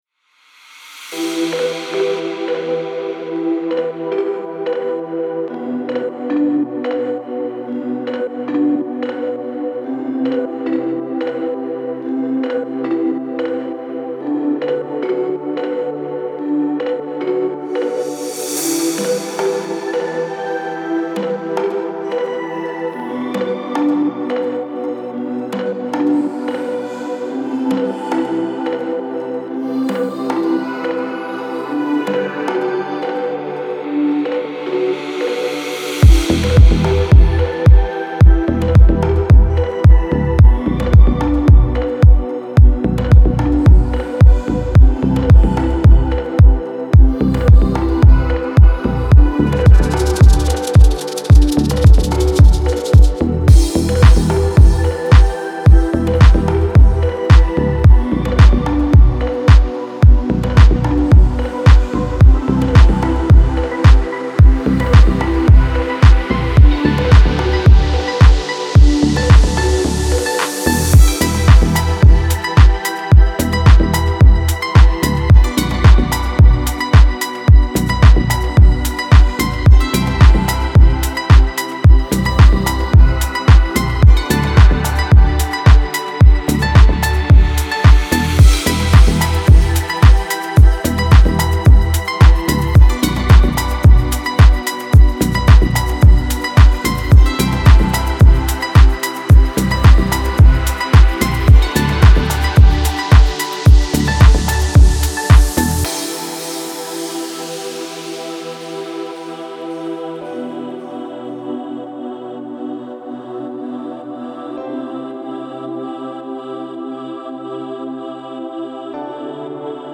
موسیقی بی کلام دیپ هاوس ریتمیک آرام